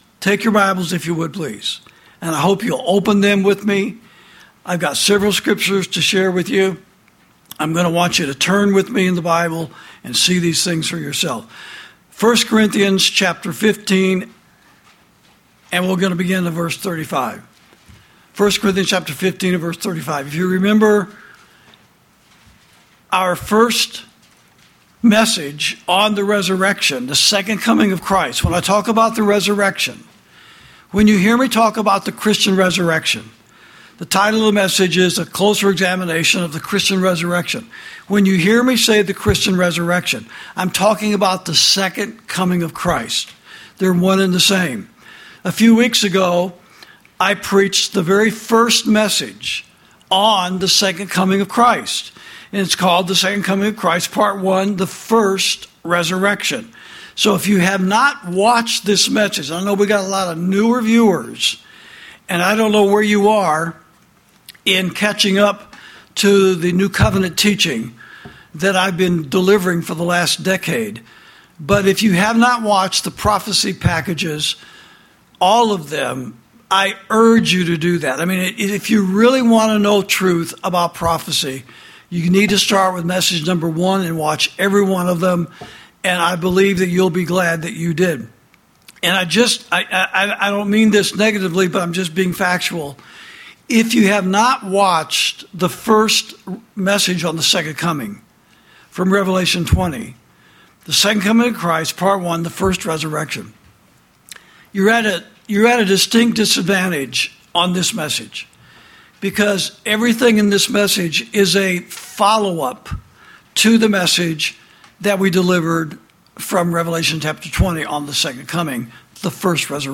This message was preached by Pastor Chuck Baldwin on Sunday, September 7, 2025, during the service at Liberty Fellowship.